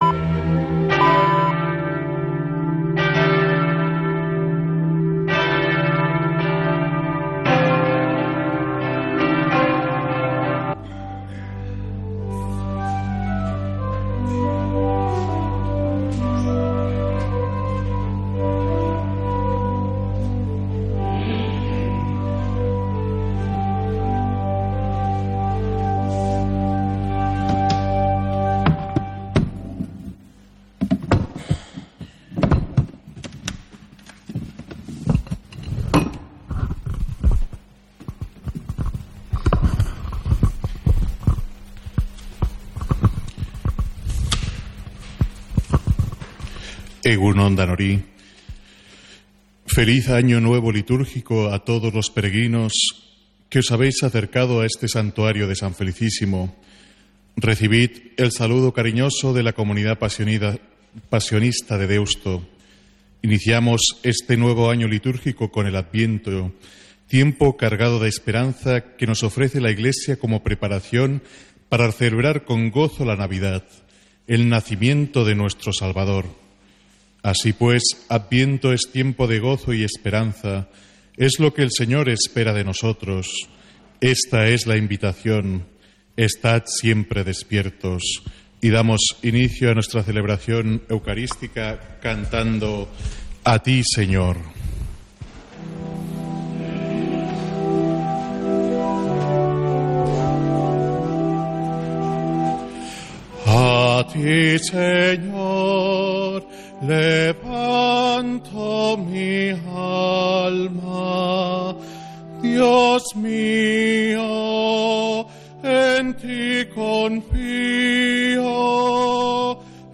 Santa Misa desde San Felicísimo en Deusto, domingo 1 de diciembre